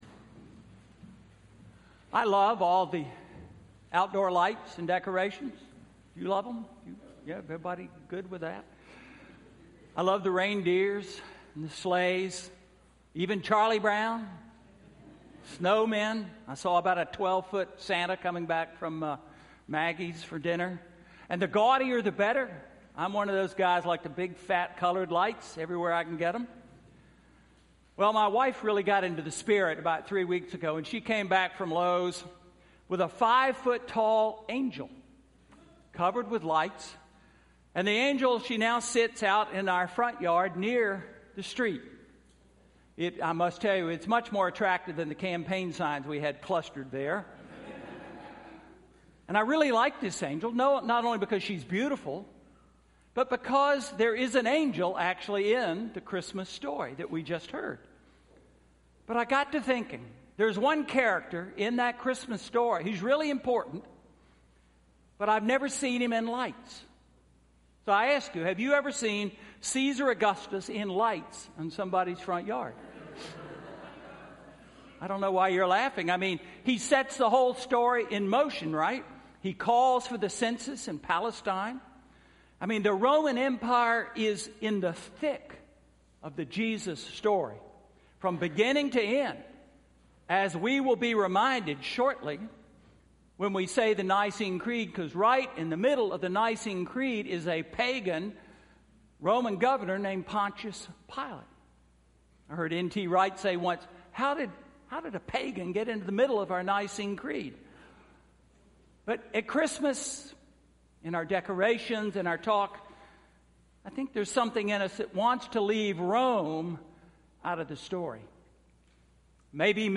Sermon–Christmas Eve–2016